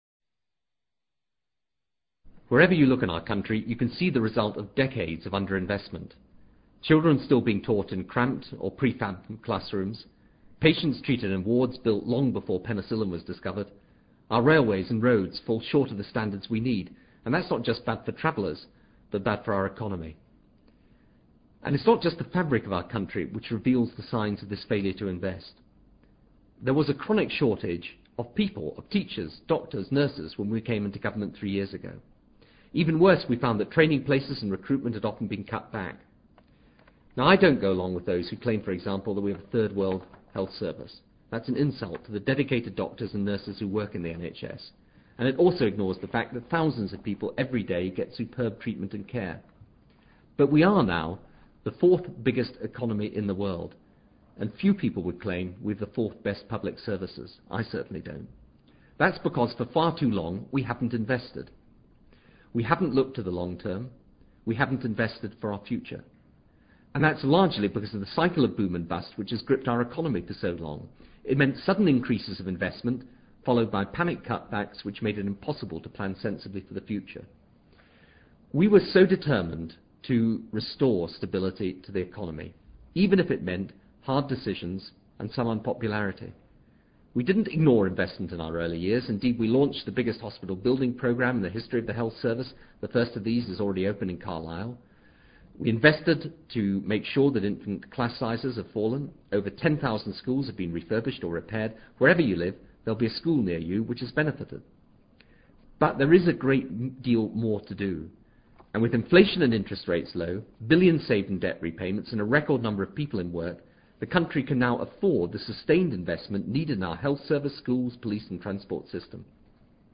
布莱尔首相演讲:Investment
Transcript of the Prime Minister's broadcast on investment